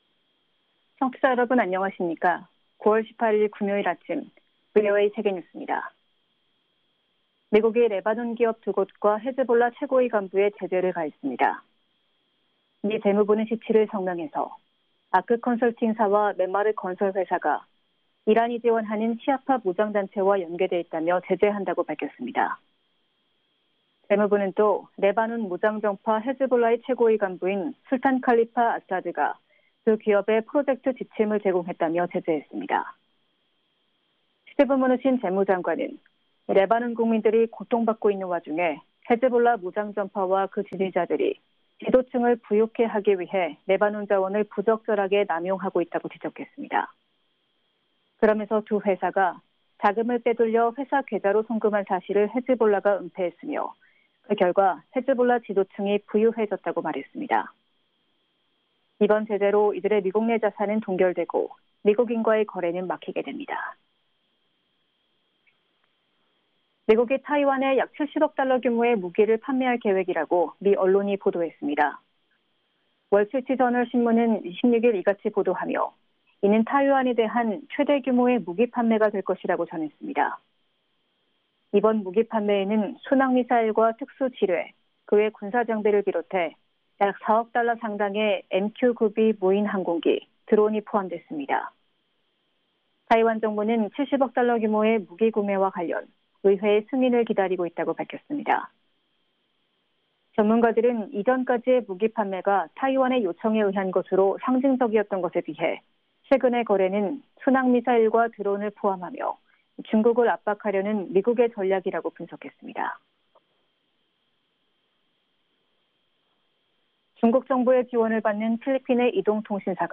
VOA 한국어 아침 뉴스 프로그램 '워싱턴 뉴스 광장' 2020년 9월 18일 방송입니다. 마크 에스퍼 미 국방장관이 미-한 동맹 등 전통적인 양자 관계로는 중국의 위협에 대처할 수 없다며 다자안보체제의 중요성을 강조했습니다. 유럽연합(EU)이 북한의 지속적인 핵 프로그램 개발에 우려를 표명하면서 북한은 핵확산금지조약에 따라 결코 핵보유국 지위를 얻을 수 없을 것이라고 밝혔습니다. 북한의 핵ㆍ미사일 역량 고도화에 대응해 무기 개발 저지 등 달성 가능한 목표의 중간 단계 합의가 필요하다는 전문가 주장이 나왔습니다.